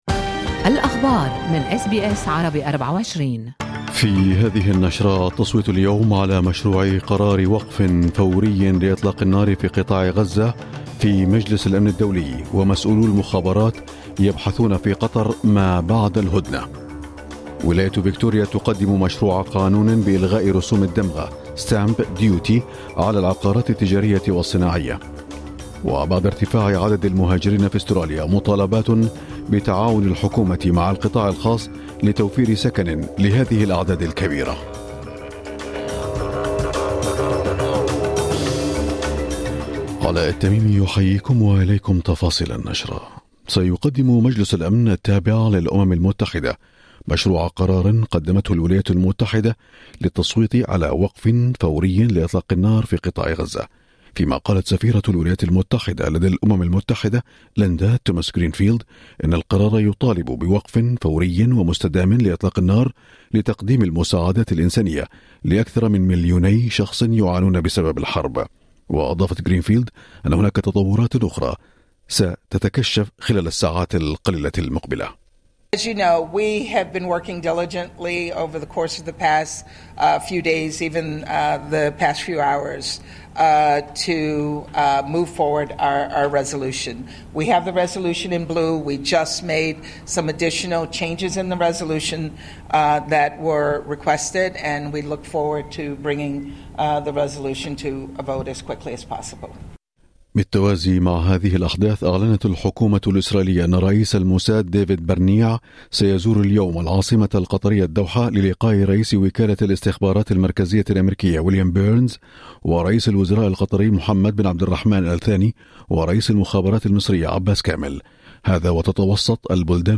نشرة أخبار المساء 22/3/2024
نشرة الأخبار